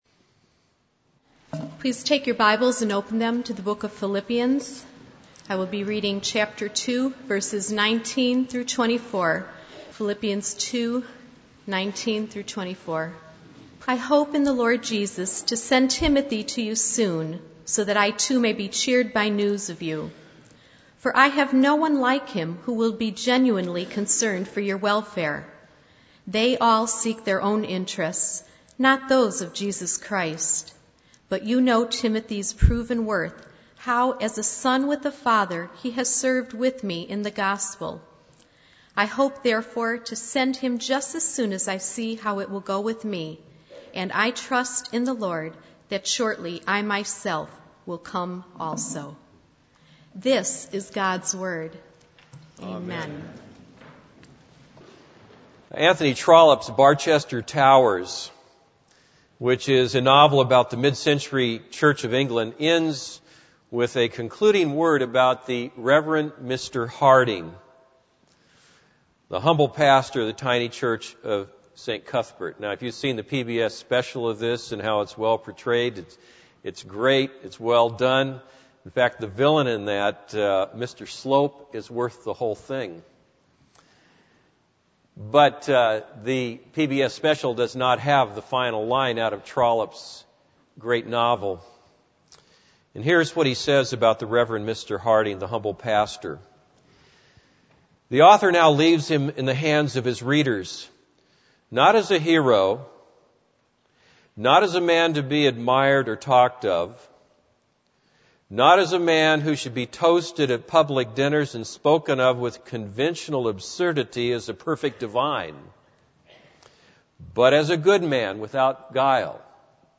This is a sermon on Philippians 2:19-24.